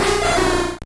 Cri de Cornèbre dans Pokémon Or et Argent.